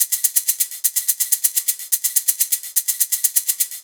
Live Percussion A 02.wav